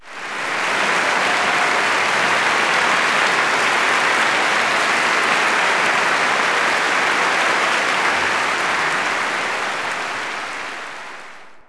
clap_045.wav